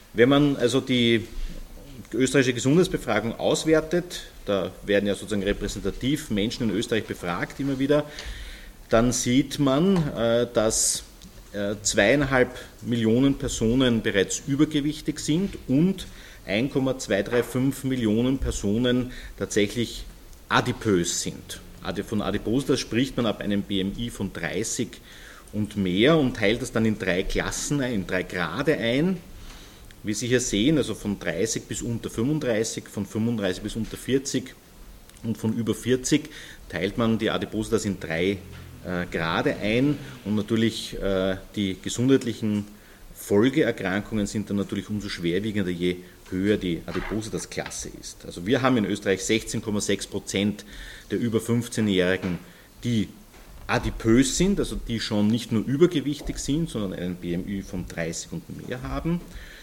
.mp3 O-Ton Dateien der Pressekonferenz vom 21.06.2022: